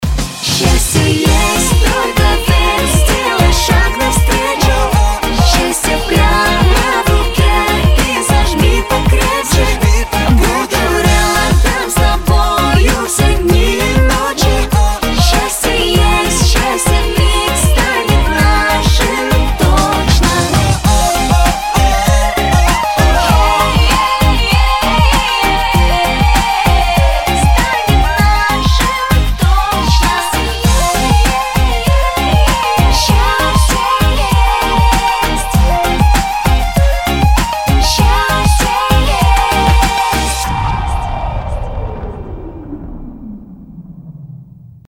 поп
позитивные
зажигательные